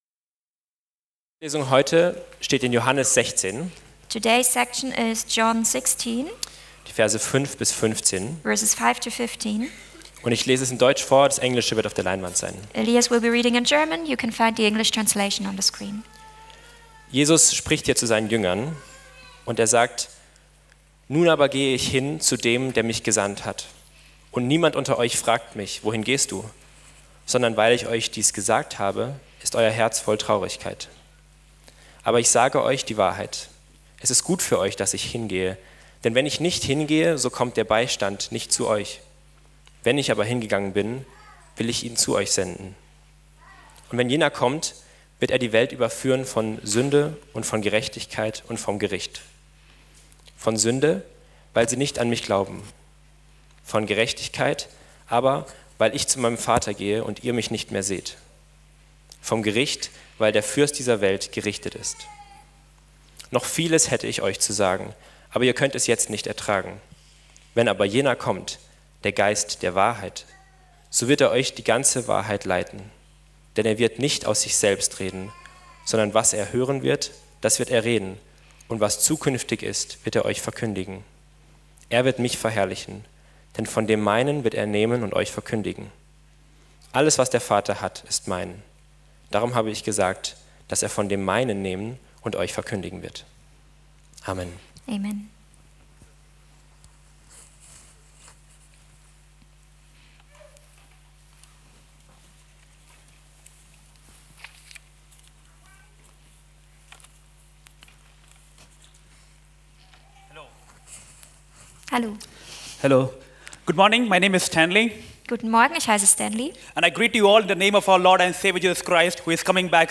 Pentecost Service / Pfingstgottesdienst ~ Specials Podcast